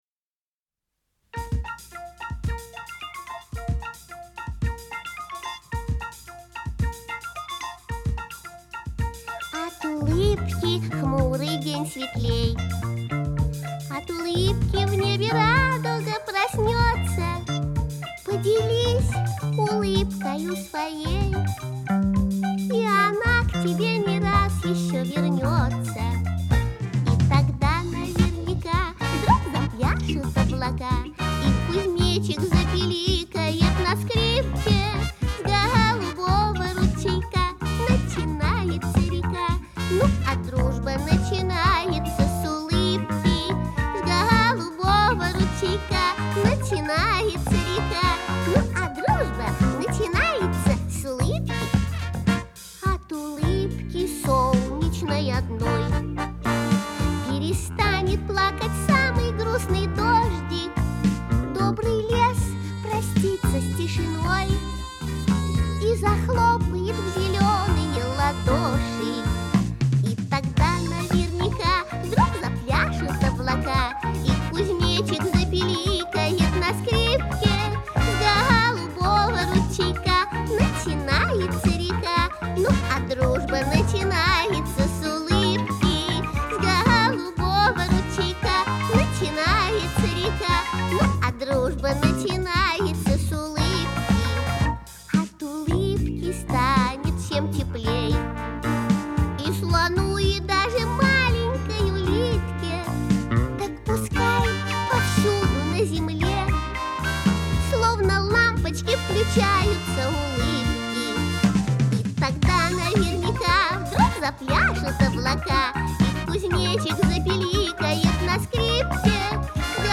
У неё был очень интересный, необычный голос.